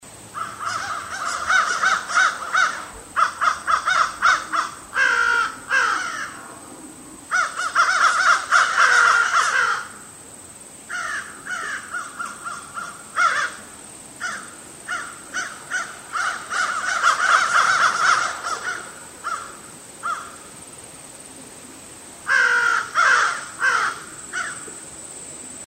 Звуки воронов
На этой странице собраны разнообразные звуки воронов – от резкого карканья до глухого клекота.